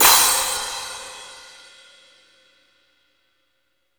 Crashes & Cymbals
pcp_crash01.wav